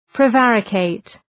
Προφορά
{prı’værə,keıt}
prevaricate.mp3